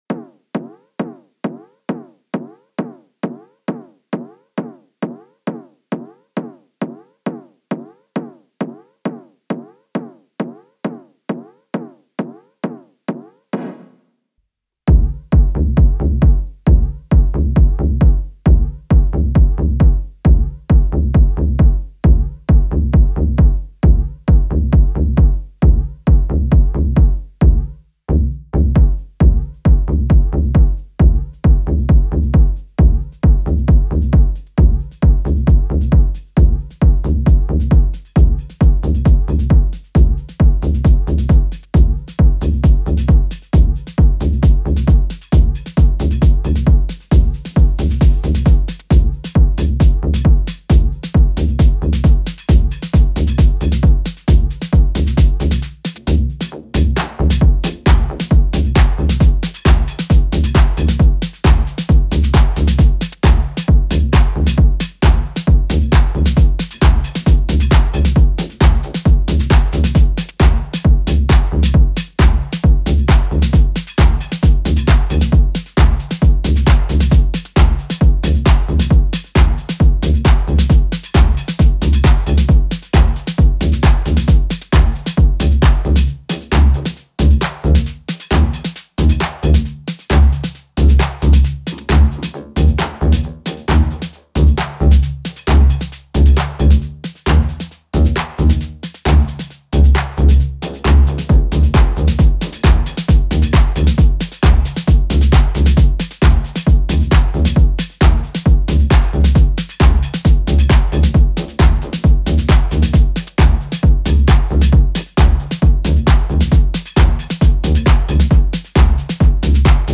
Syntakt + Ableton FX techno fun.